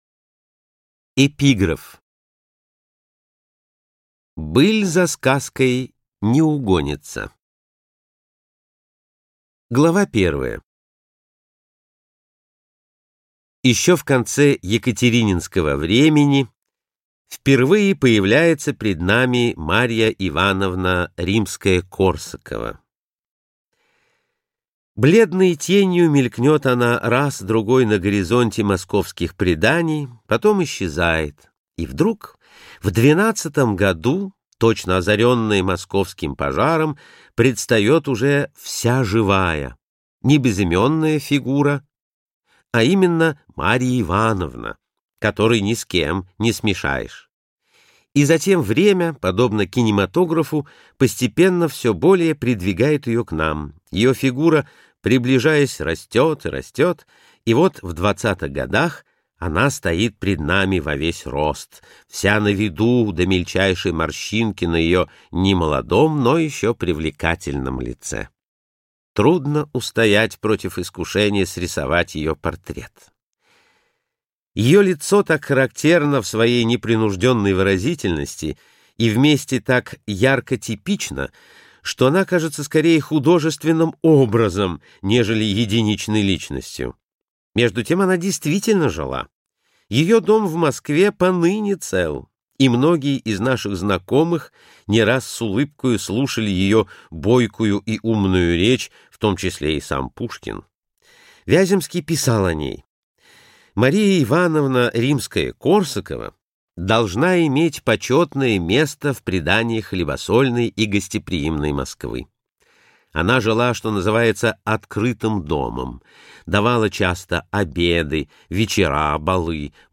Аудиокнига Грибоедовская Москва | Библиотека аудиокниг